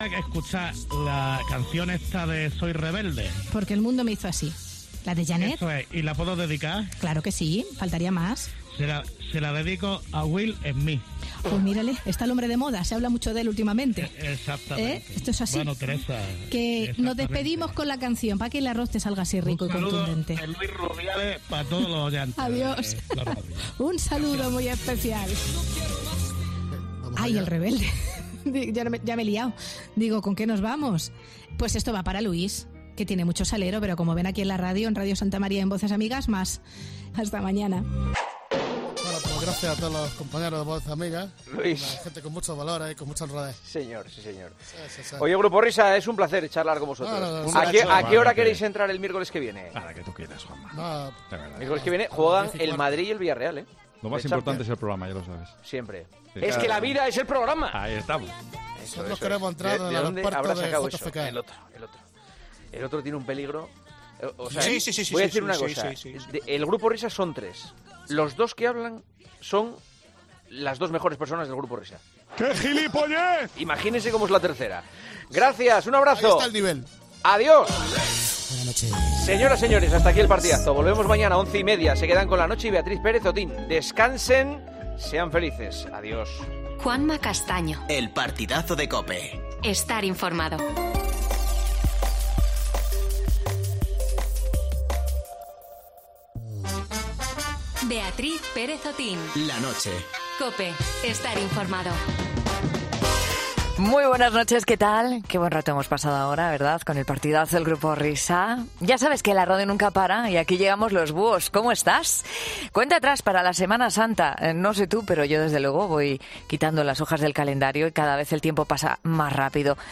Por este motivo, en 'La Noche' de COPE te contamos cómo están reclutando empleados en la Costa del Sol.